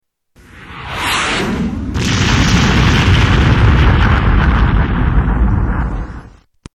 Incoming missile and major explosion